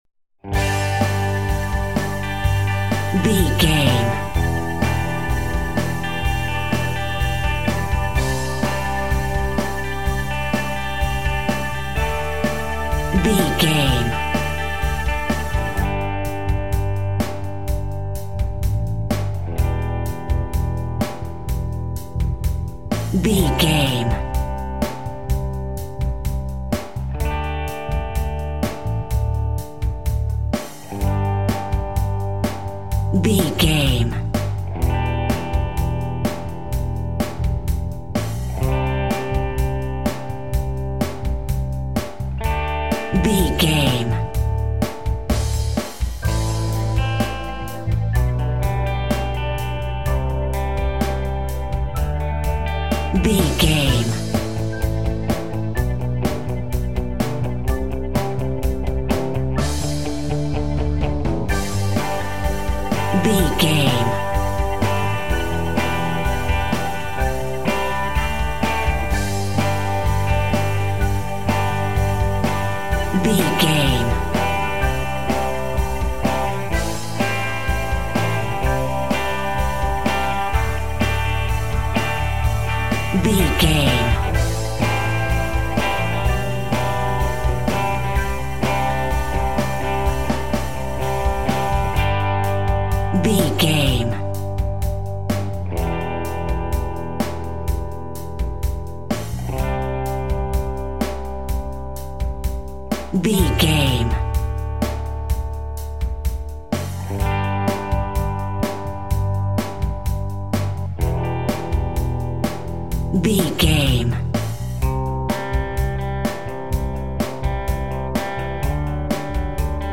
Ionian/Major
cheesy
Power pop
drums
bass guitar
electric guitar
piano
hammond organ